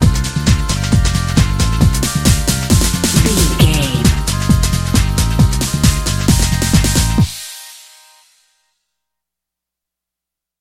Ionian/Major
Fast
synthesiser
drum machine